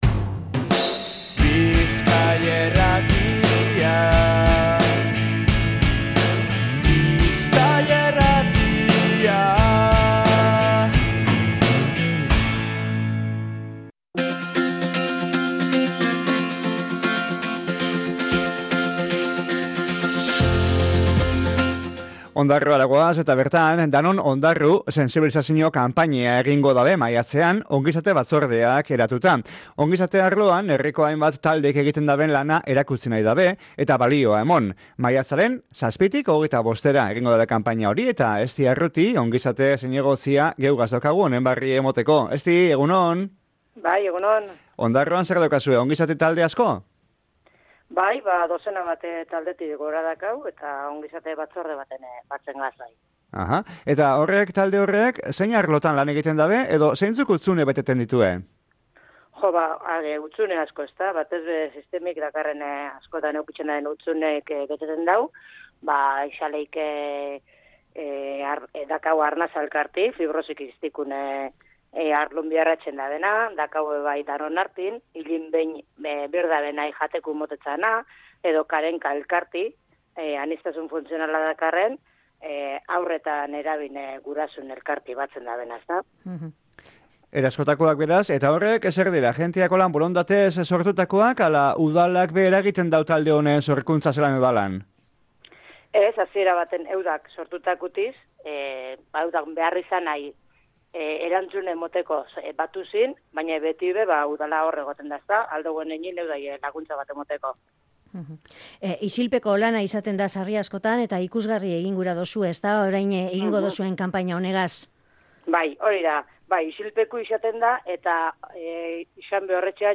Esti Arruti Ongizate arloko zinegotziak esan deusku egiten dabena ezinbestekoa dala, beraz, ekitaldiokin aintzat hartu, eskertu, balioa emon eta ezagutzera emon gura dituela.